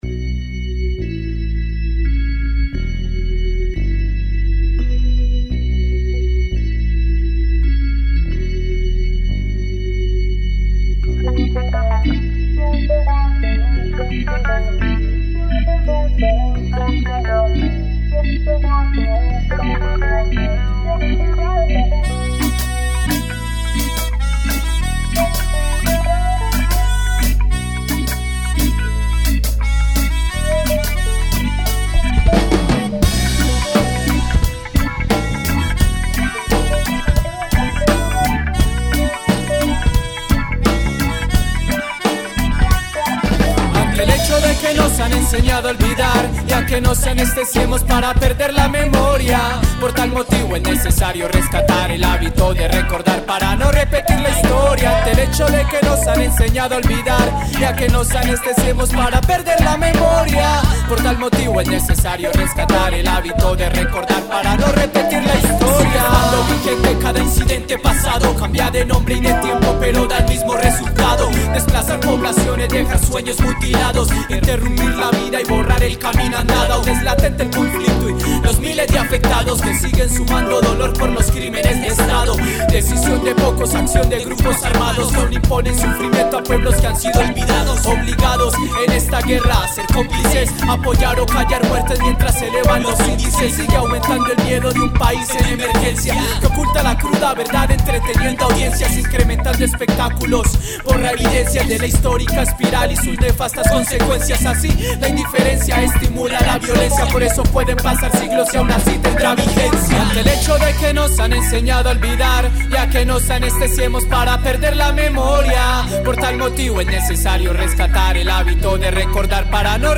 Canción
bajo y teclados.
coros y segunda voz.
melódica y voz.